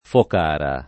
[ fok # ra ]